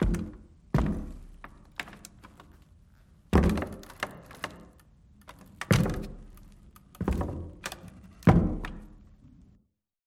amb_fs_stumble_wood_07.mp3